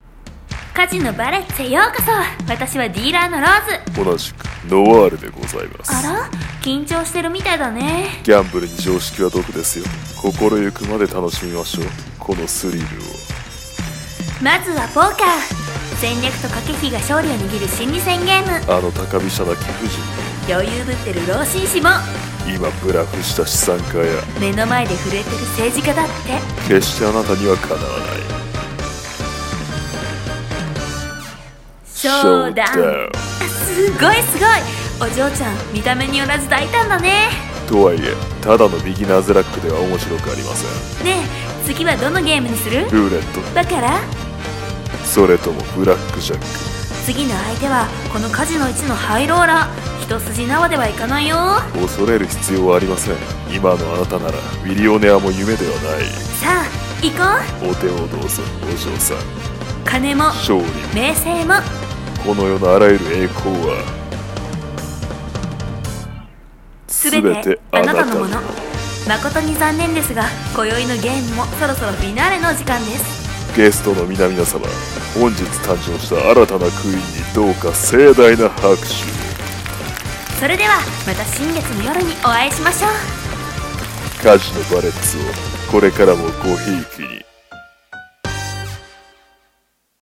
【声劇台本】Bet your Life!【2人用】